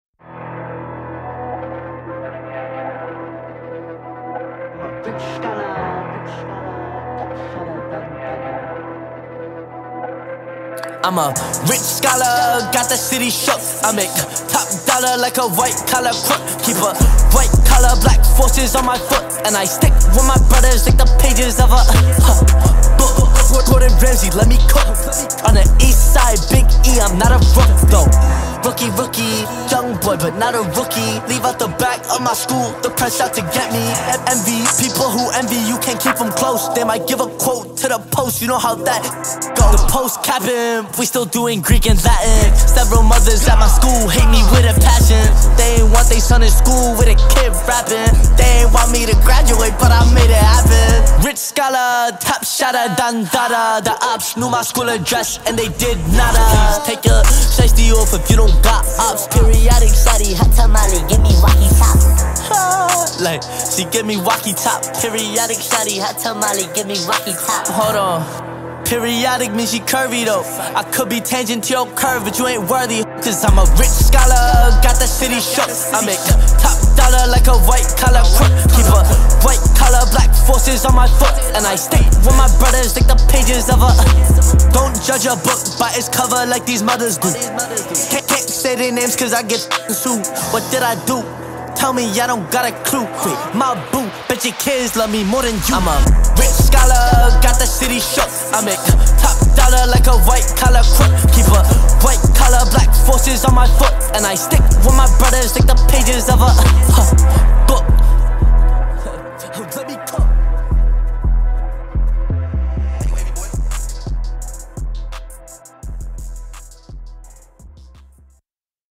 сочетая рэп с актуальными темами.